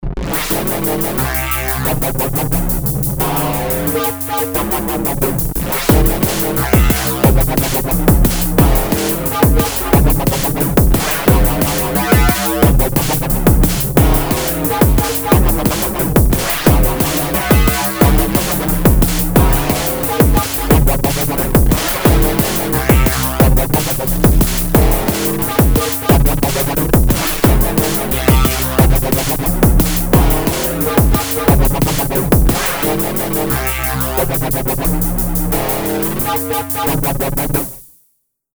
Metallic DnB bass on the DN
I’m not that experienced (with these types of basses) but I’ve done this and it’s just a massive use of P-locks and finding sweet spots with LFO tempos and depth.
LFO1 is messing with Level of B
LFO2 is messing with Ratio of B
Drive=127 (E-knob on amp page 1)
100% Chorus